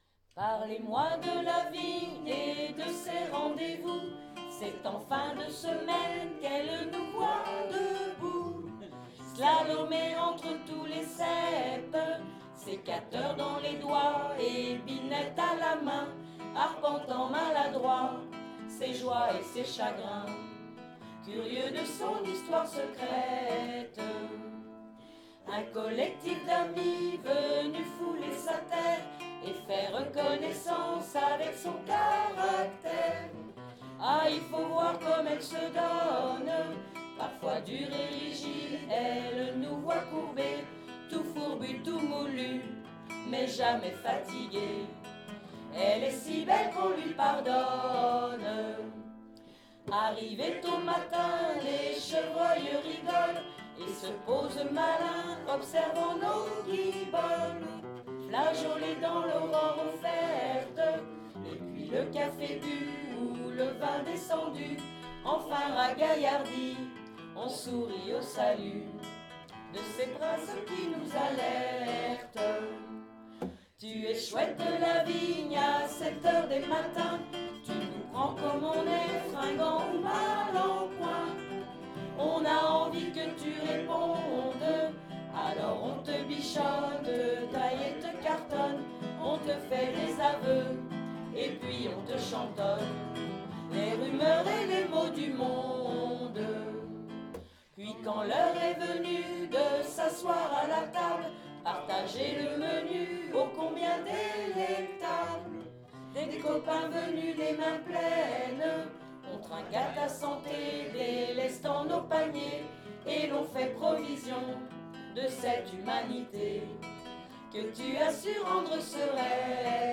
Lorage__Beauregard.ogg